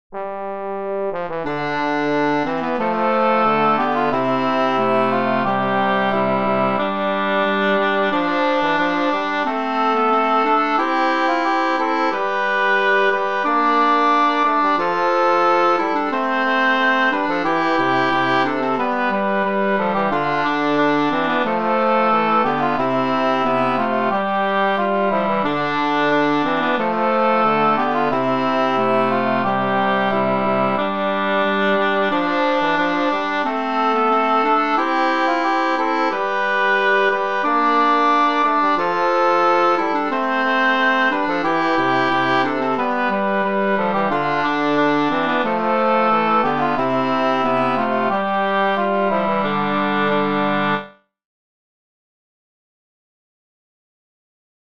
Hey downe a downe: a canon at the fifth
This means that each part starts their repetition of the tune a fifth above the previous part.
In this case the lowest part, the bass, starts, the tenor comes in a measure later a fifth above where the bass started, and another measure later the treble comes in a fifth above where the tenor started, or, in other words, a ninth above where the bass started.